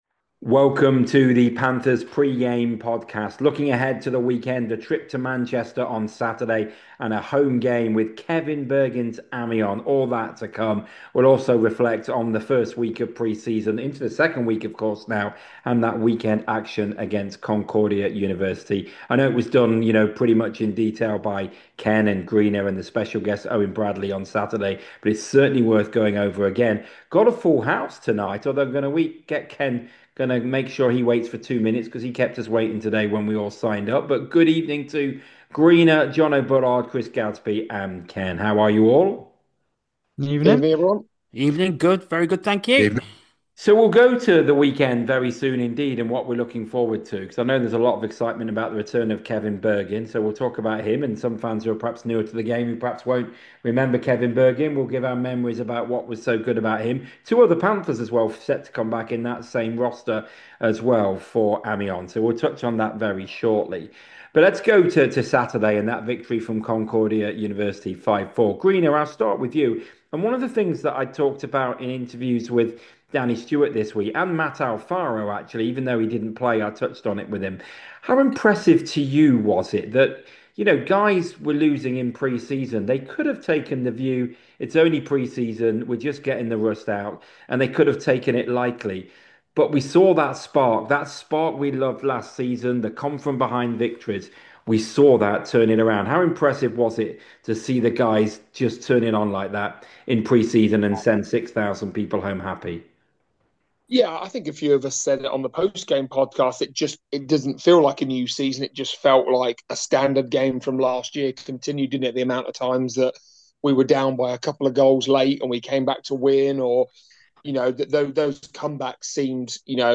The five guys talk about last weekend's victory over Concordia Stingers as well as looking ahead to two matches this weekend - on the road at Manchester Storm on Saturday and home to Amiens on Sunday.